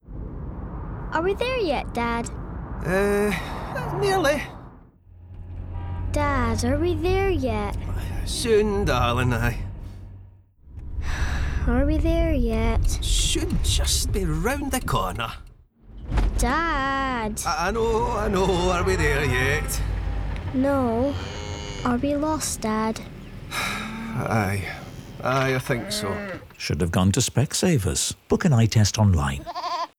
Scottish 40's - 'RSC', 'Victoria & Abdul', 'Outlander', 'Shetland'